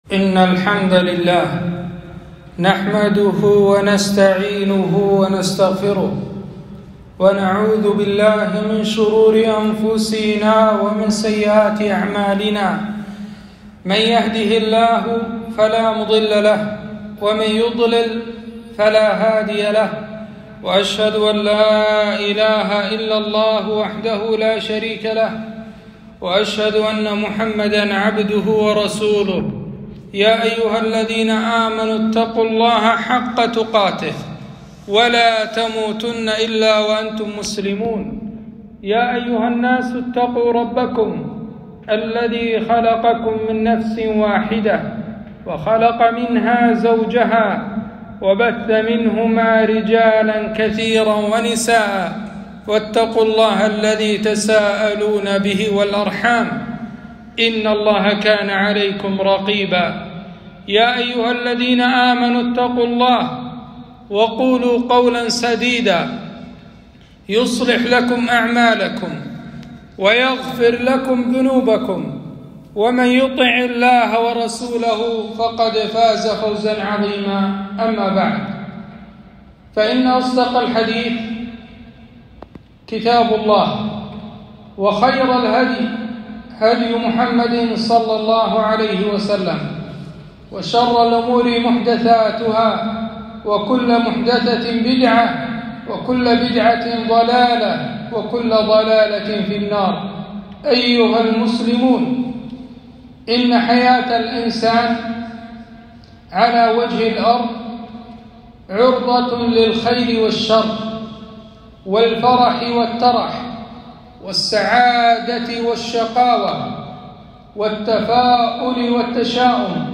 خطبة - أسباب الهلاك والنجاة